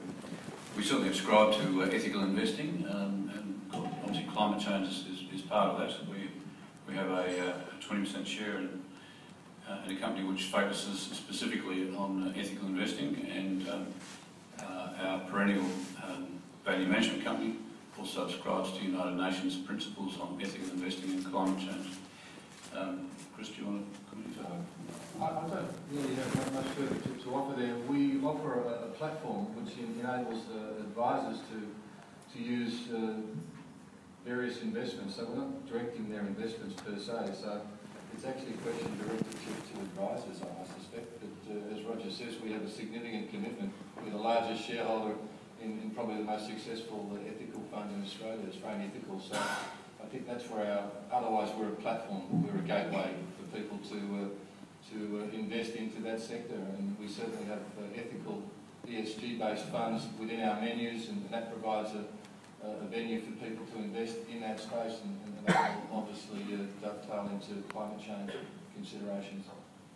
At the company’s AGM in Melbourne today, shareholders questioned IOOF’s board on its approach to climate change risk, also extending the question to external auditors KPMG.